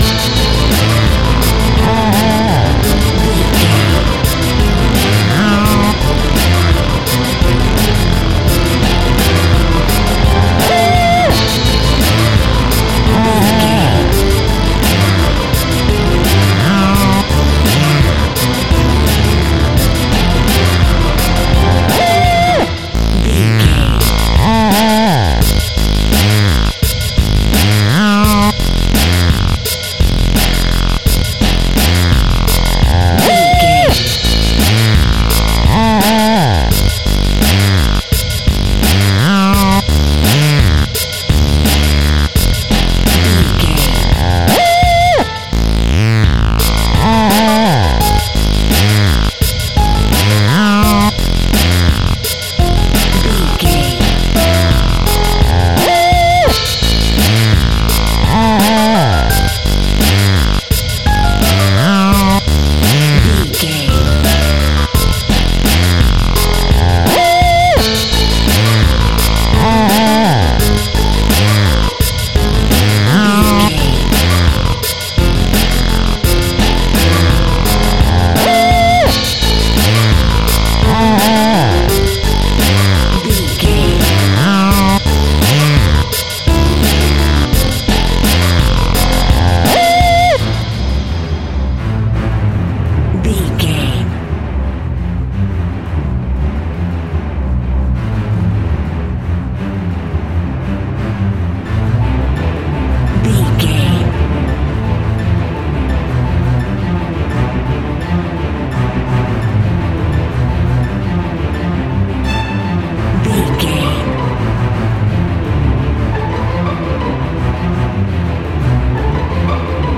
Creepy Scary Music Cue.
In-crescendo
Aeolian/Minor
tension
ominous
eerie
synths
Synth Pads
atmospheres